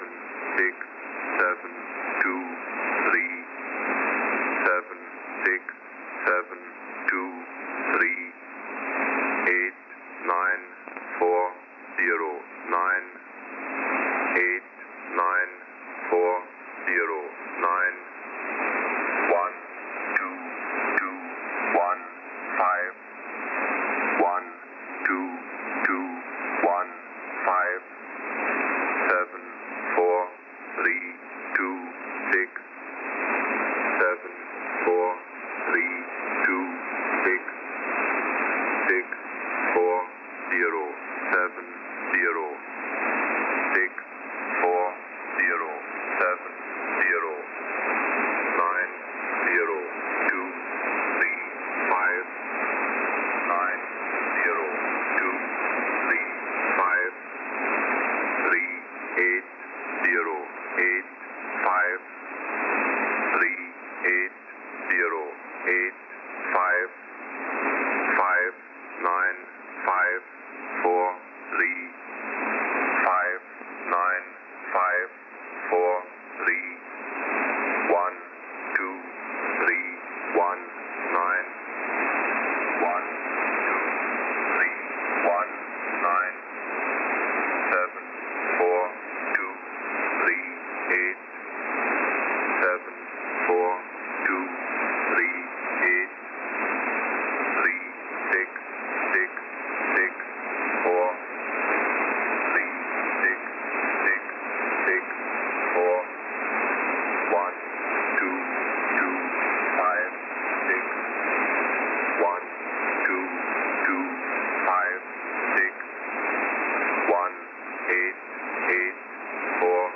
Mode: USB